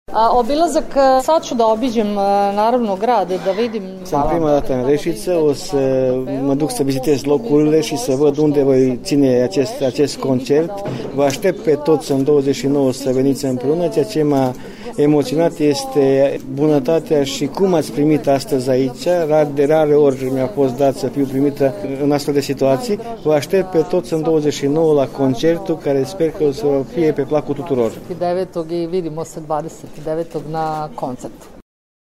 traducerea a fost asigurată